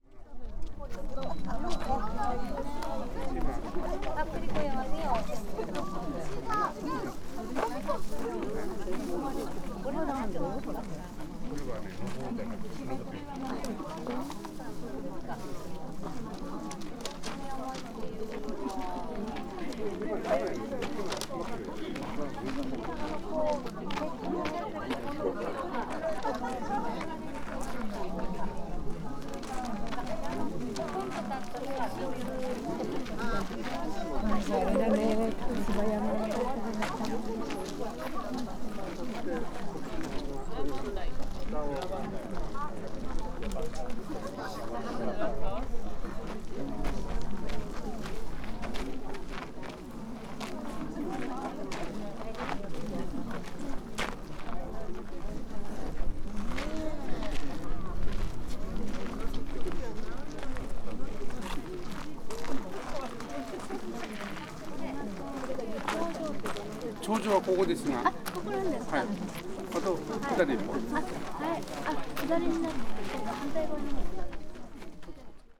Fukushima Soundscape: Mt. Hanami